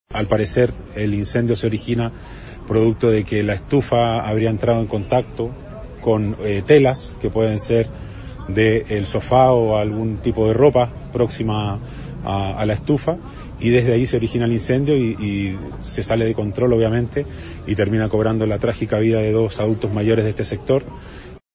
Con respecto a la presunta causa del inicio del fuego, fue el alcalde de la comuna, Germán Codina, quien dijo que todo se debería a una estufa a gas. Esta habría entrado en contacto con algún material inflamable del mismo domicilio, dijo.